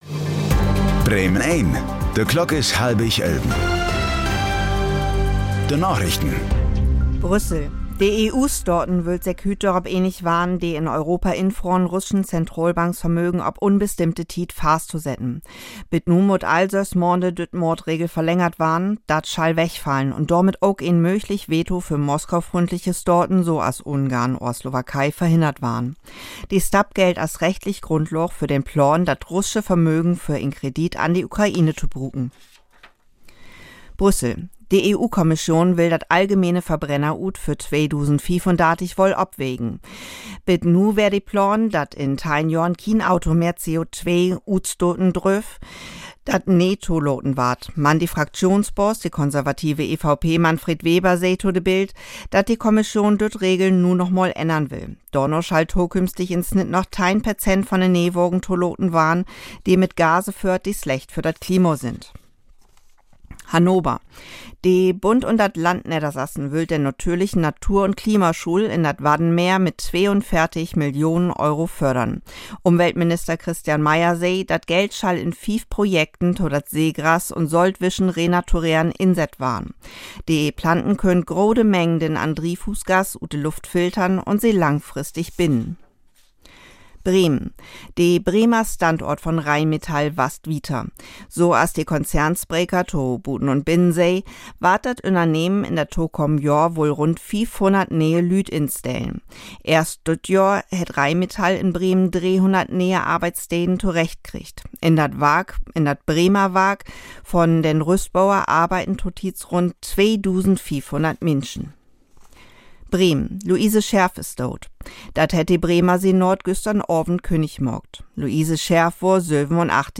Plattdüütsche Narichten vun'n 12. Dezember 2025